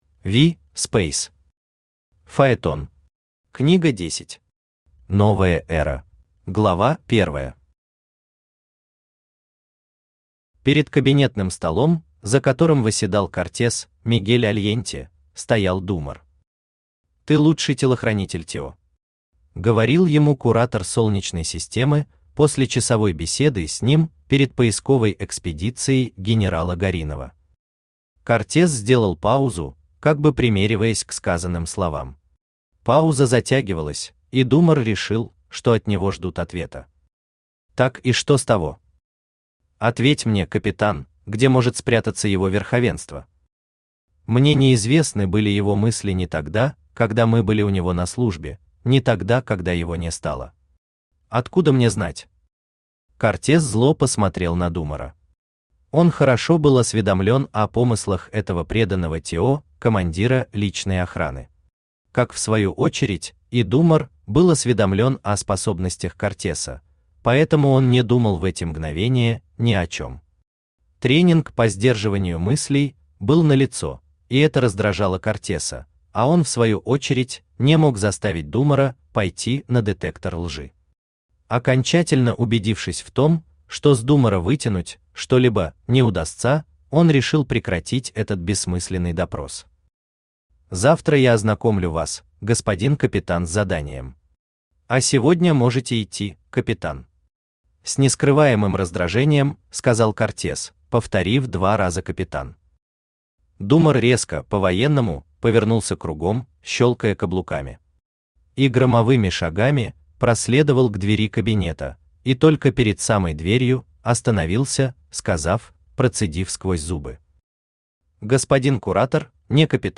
Новая Эра Автор V. Speys Читает аудиокнигу Авточтец ЛитРес.